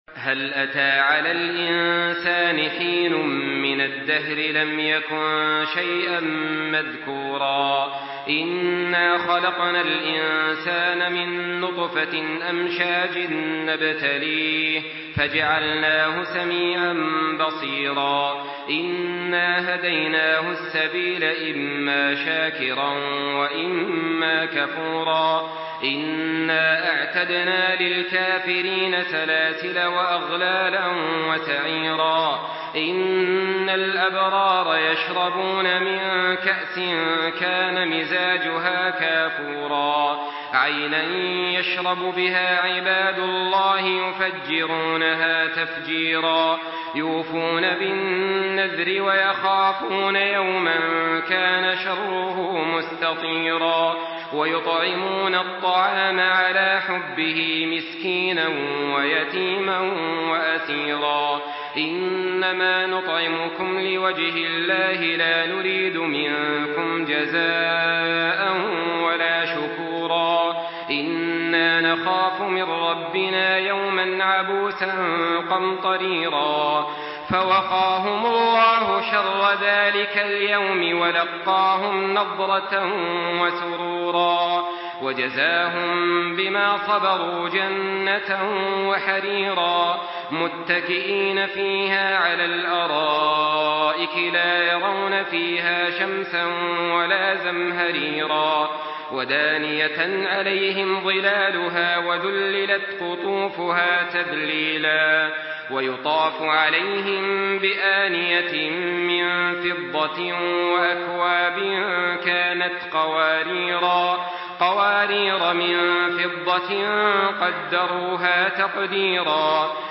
تحميل سورة الإنسان بصوت تراويح الحرم المكي 1424
مرتل